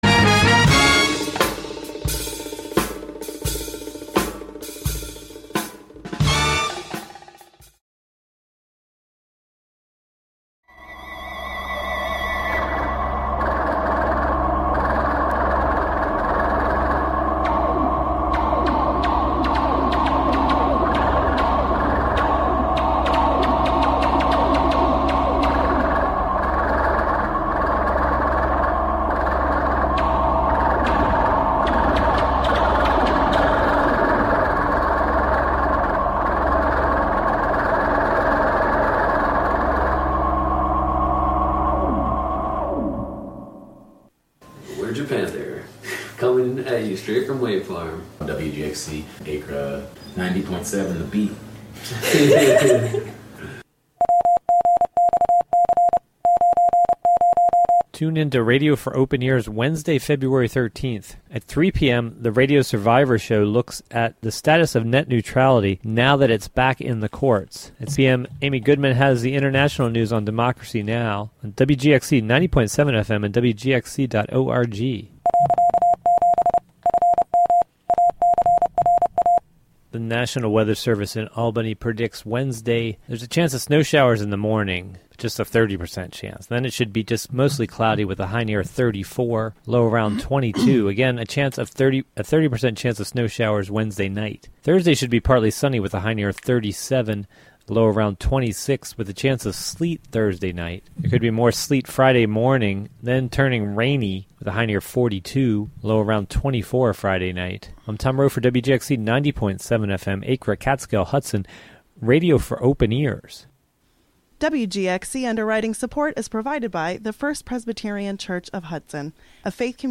An upbeat music show featuring the American songbook, as interpreted by contemporary artists as well as the jazz greats in a diverse range of genres.